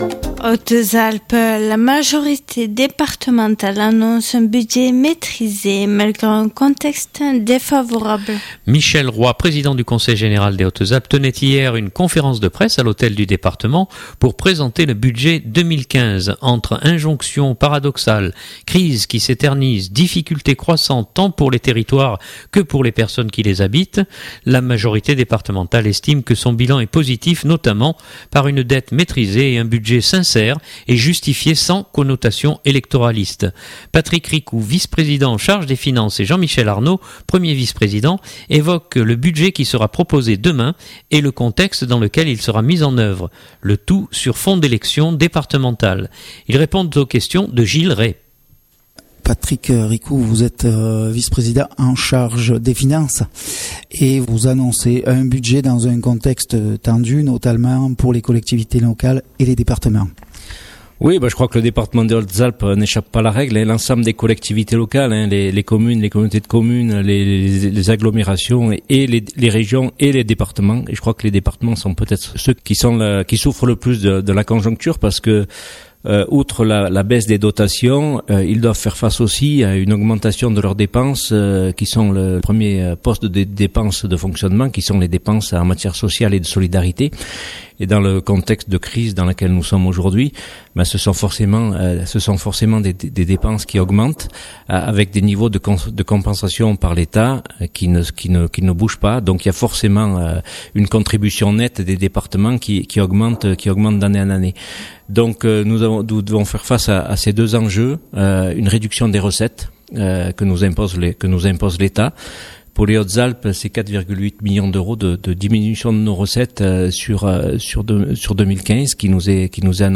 Entre injonctions paradoxales, crise qui s’éternise, difficultés croissantes tant pour les territoires que pour les personnes qui les habitent, la majorité départementale estime que son bilan est positif notamment par une dette maitrisée et un budget sincère et justifié sans connotation électoraliste. Patrick Ricou vice-président en charge des finances et Jean-Michel Arnaud, 1er vice-président évoquent le budget qui sera proposé demain et le contexte dans lequel il sera mis en œuvre.